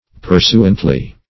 Pursuantly \Pur*su"ant*ly\, adv. Agreeably; conformably.